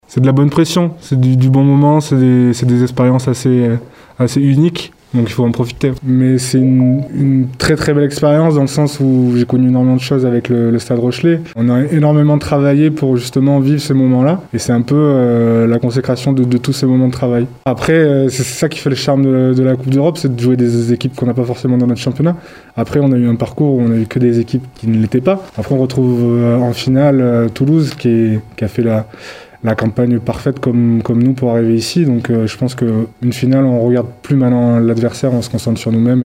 On écoute le 3e ligne Kévin Gourdon :